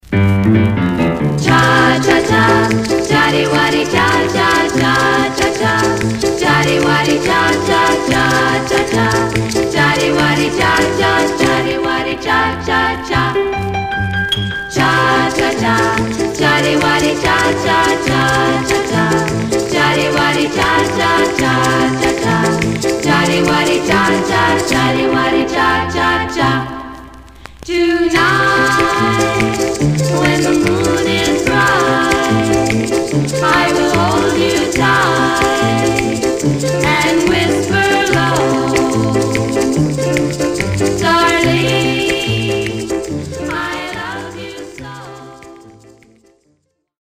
Stereo/mono Mono
Black Female Group